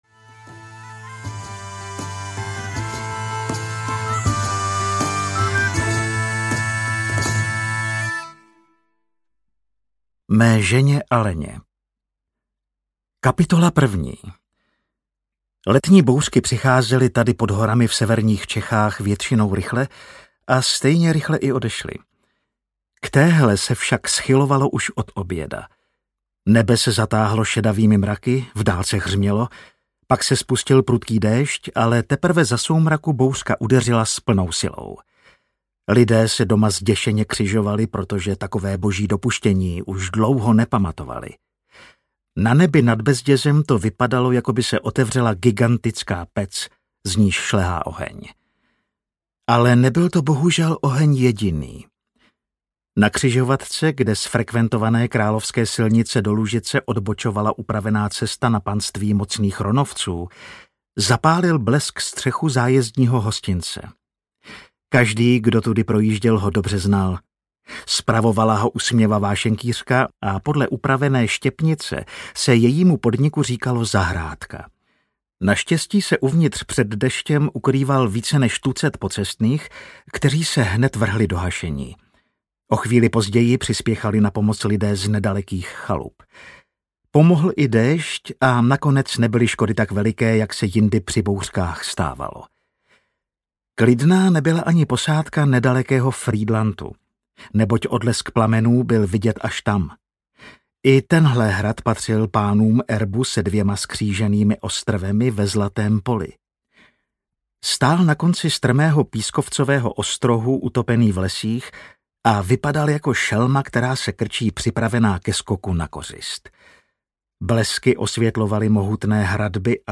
Ukázka z knihy
• InterpretAleš Procházka